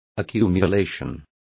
Complete with pronunciation of the translation of accumulation.